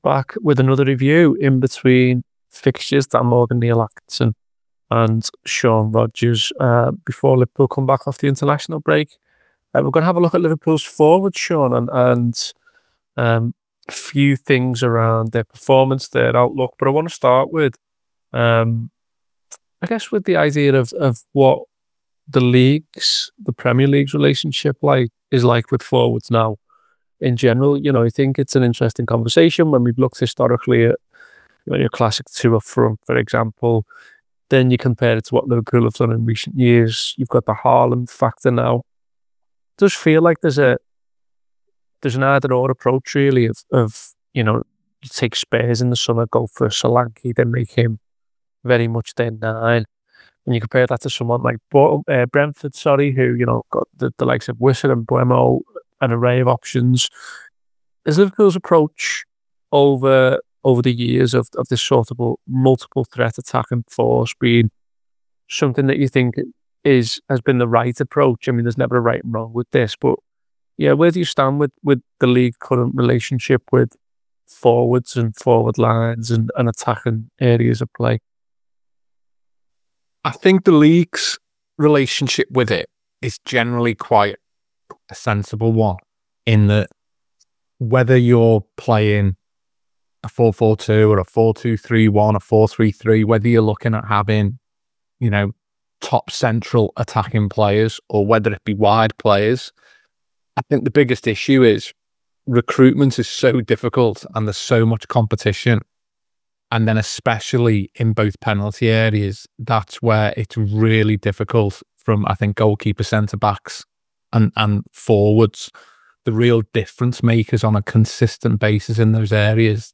Below is a clip from the show- subscribe for more review chat around Liverpool’s season so far…